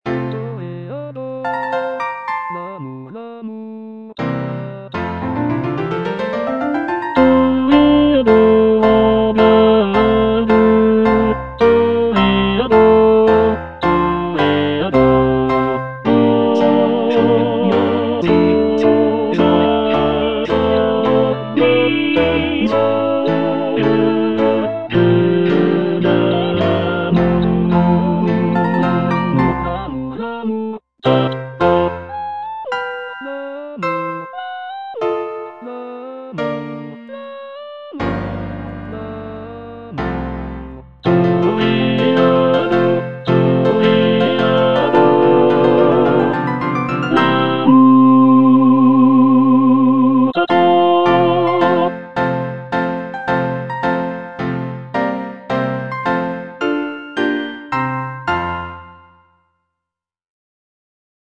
G. BIZET - CHOIRS FROM "CARMEN" Toreador song (II) (tenor II) (Emphasised voice and other voices) Ads stop: auto-stop